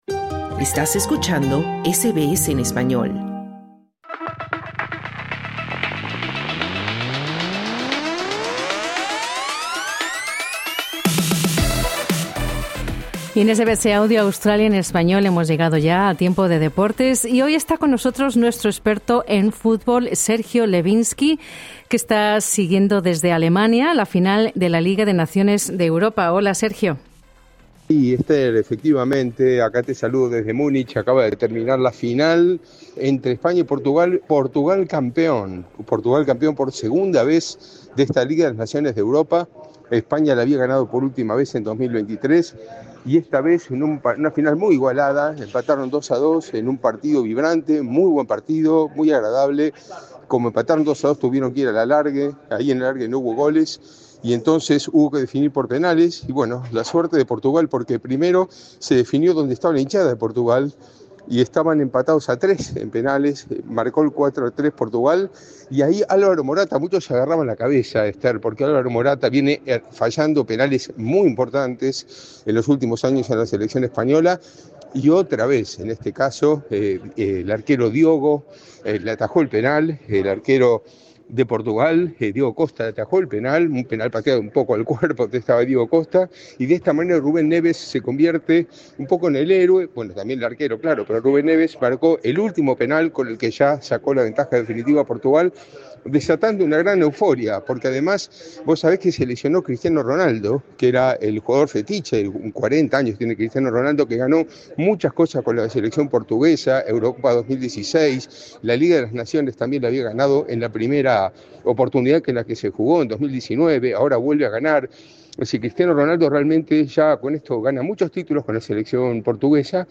El equipo portugués, liderado por Cristiano Ronaldo, venció en un emocionante partido a España. La final de la UEFA Nations League se dirimió por penales luego de un empate a dos goles durante el tiempo regular. Escucha el informe de nuestro enviado especial en Alemania